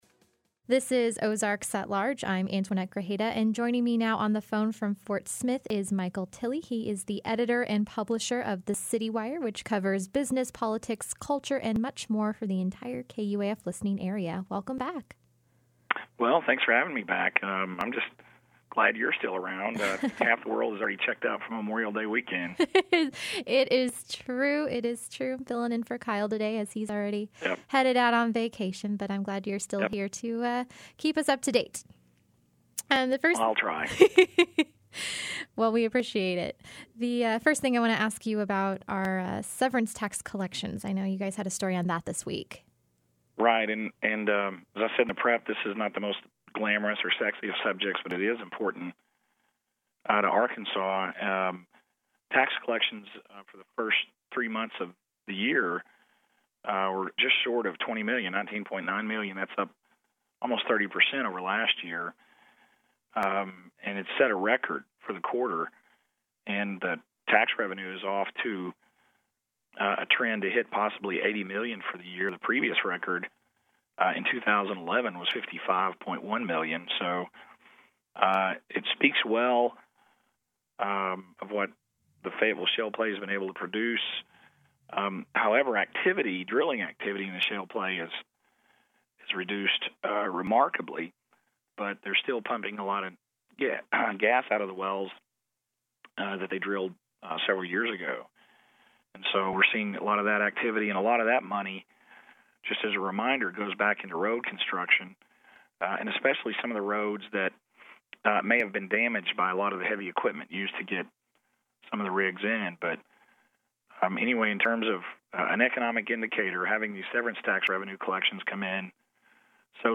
In our weekly conversation